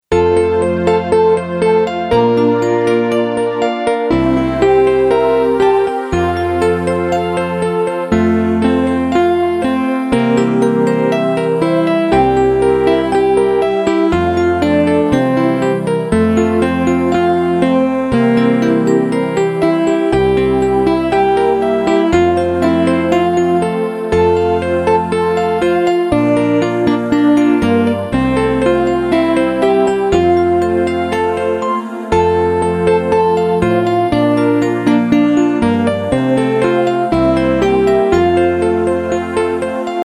- Mp3 Instrumental Song Track